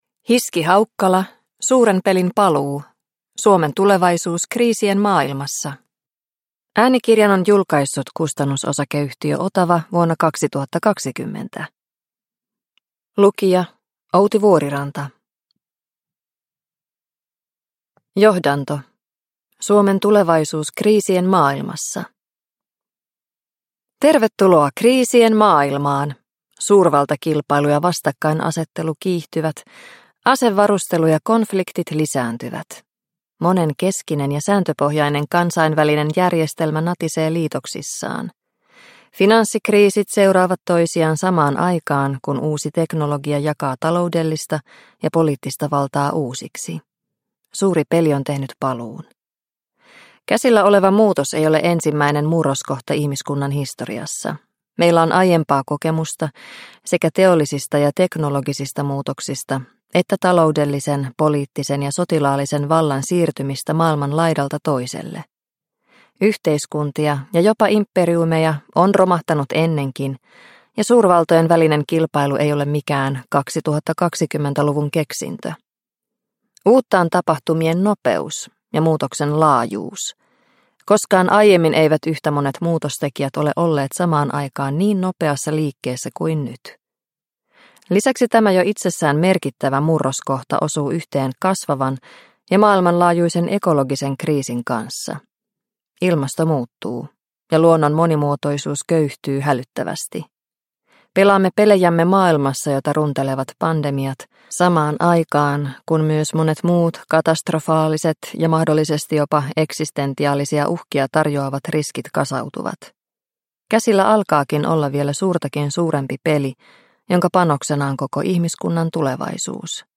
Suuren pelin paluu – Ljudbok – Laddas ner